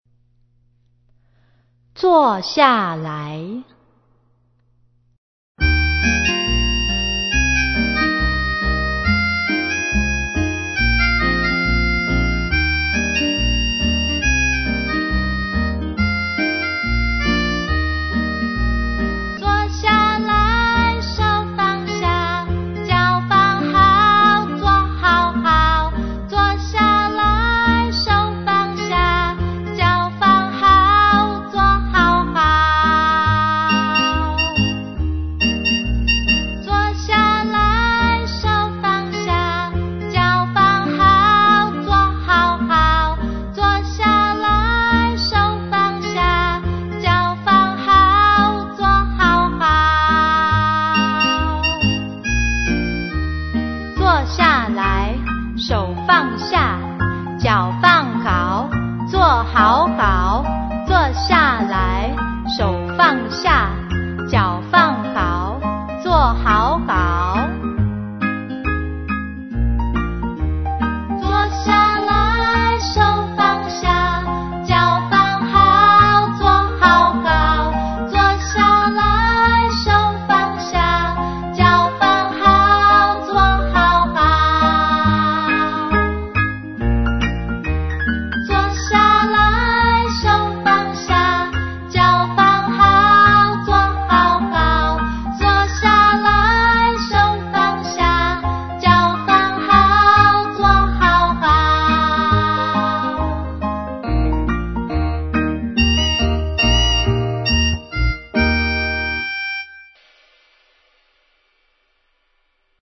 CD每首兒歌均譜寫輕快活潑的唱曲